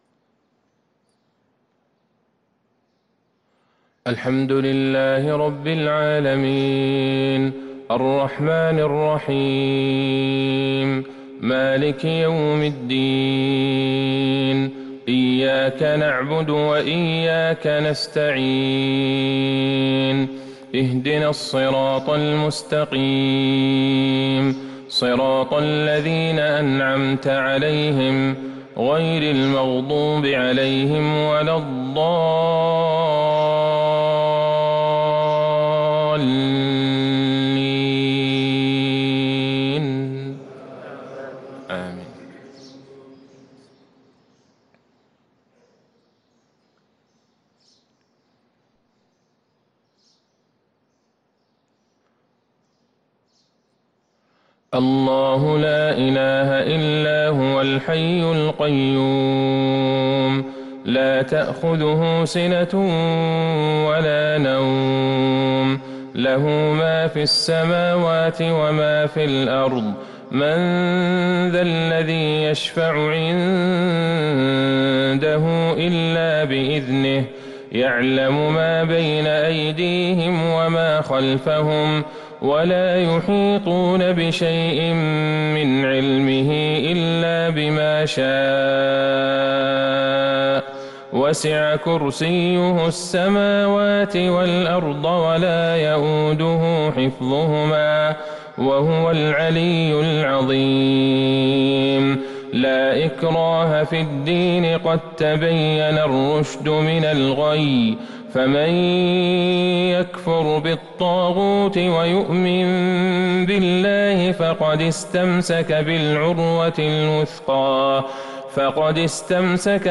فجر الأربعاء ١ صفر ١٤٤٣هـ | تلاوة من سورة البقرة | Fajr prayer from Surah Al-Baqarah 8-9-2021 > 1443 🕌 > الفروض - تلاوات الحرمين